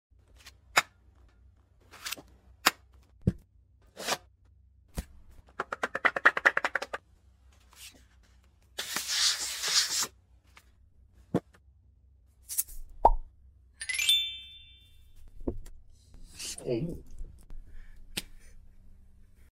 The Water Sound sound effects